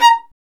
Index of /90_sSampleCDs/Roland L-CD702/VOL-1/STR_Violin 2&3vb/STR_Vln2 _ marc